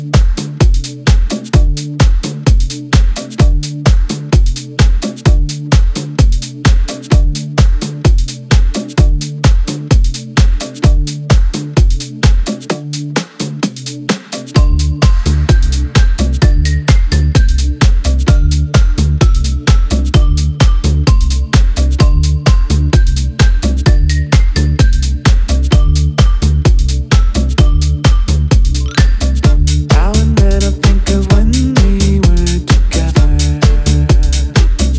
Жанр: Хаус
# House